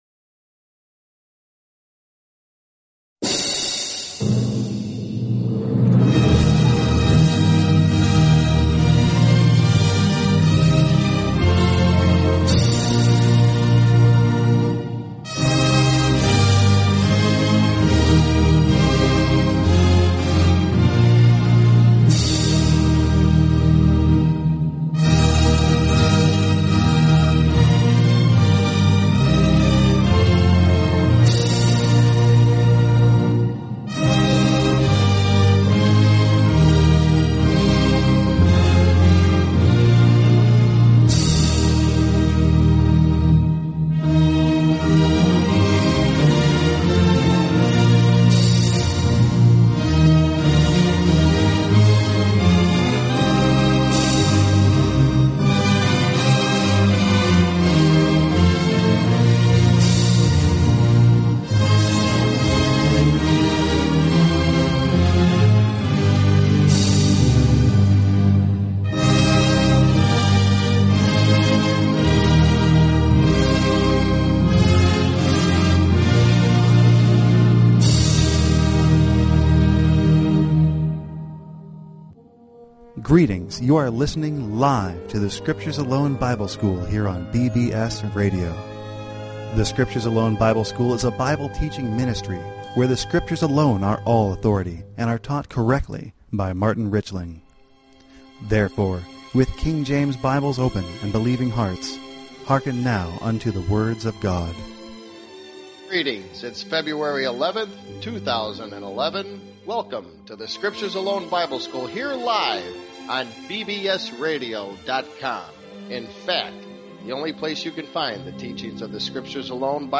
Talk Show Episode, Audio Podcast, The_Scriptures_Alone_Bible_School and Courtesy of BBS Radio on , show guests , about , categorized as